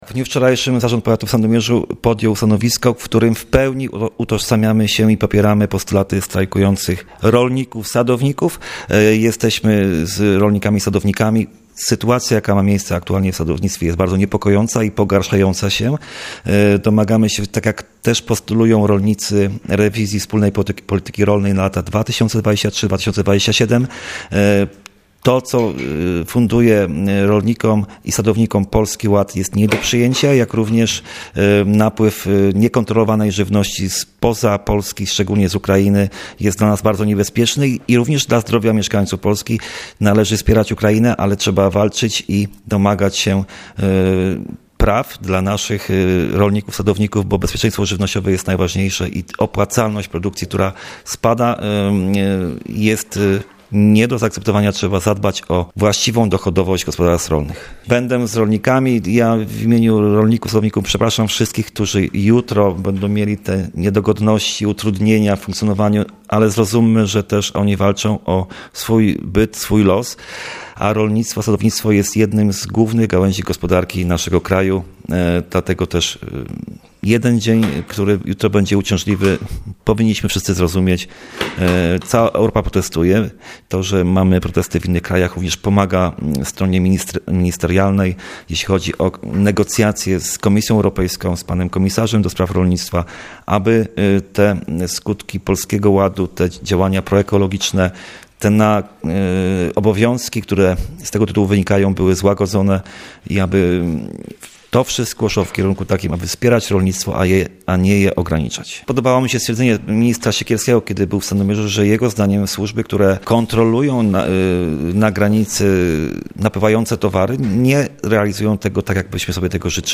Mówi starosta Marcin Piwnik: